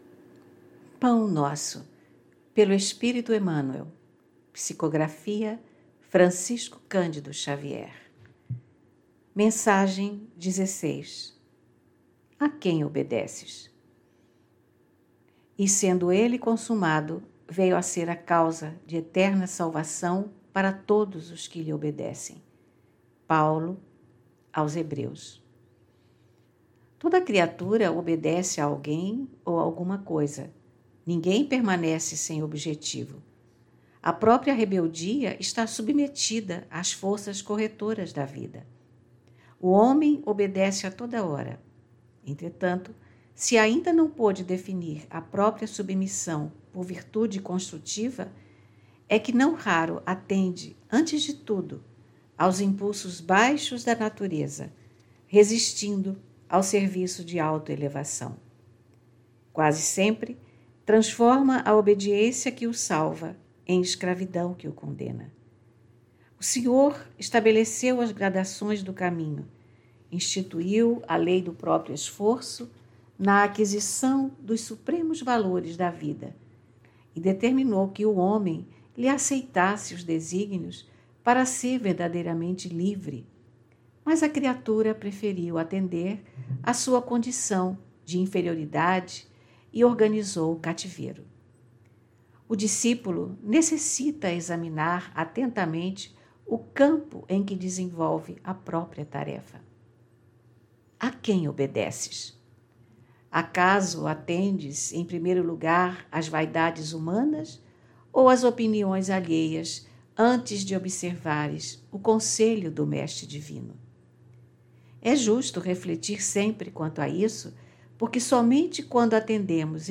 Leitura e comentários do livro Pão Nosso, pelo espirito Emmanuel, psicografia de Francisco Candido Xavier.